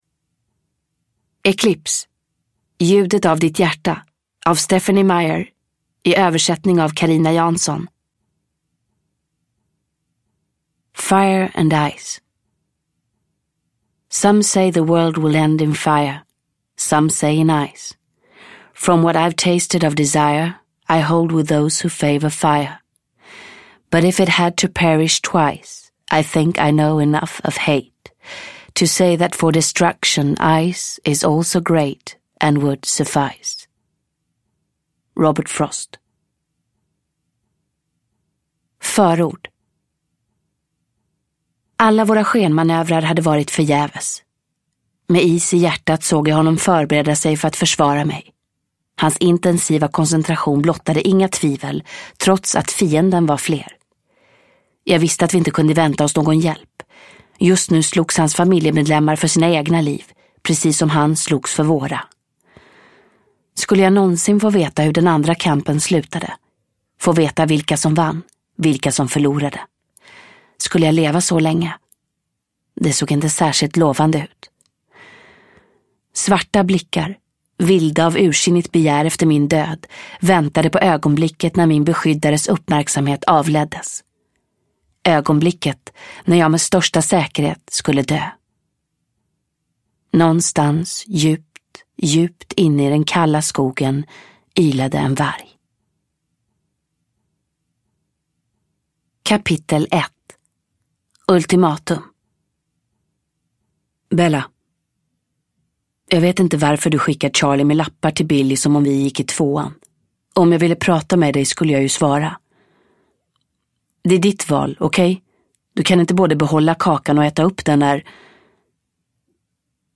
Ljudet av ditt hjärta – Ljudbok